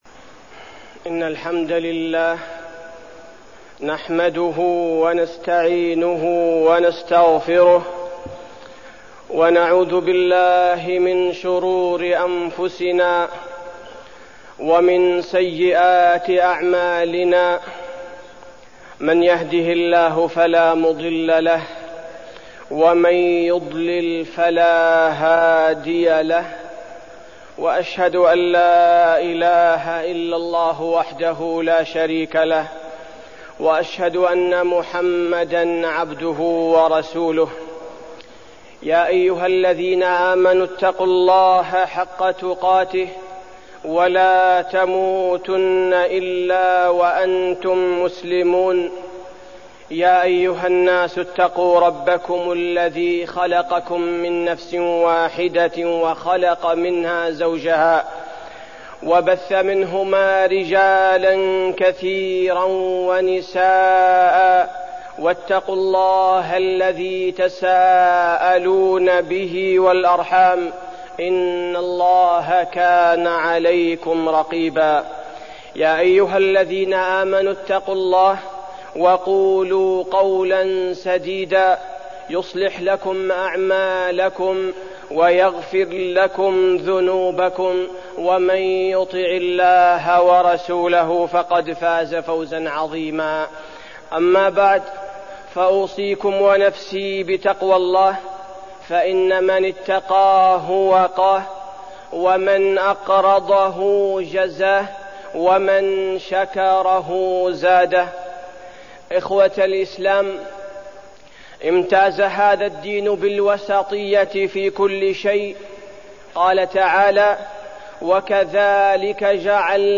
تاريخ النشر ٢٦ محرم ١٤١٩ هـ المكان: المسجد النبوي الشيخ: فضيلة الشيخ عبدالباري الثبيتي فضيلة الشيخ عبدالباري الثبيتي الإسلام دين وسط